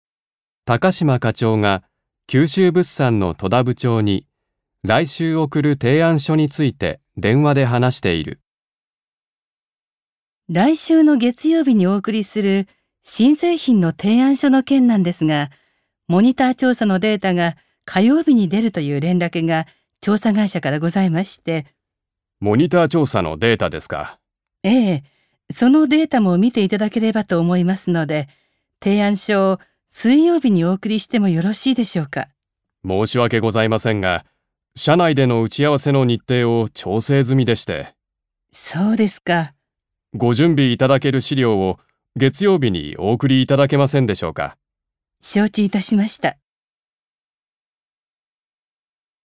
1. 会話（許可きょかもとめる・許可きょかをしない ）
場面：高島課長たかしまかちょう九州物産きゅうしゅうぶっさん戸田部長とだぶちょうに来週送る提案書ていあんしょについて電話で話している。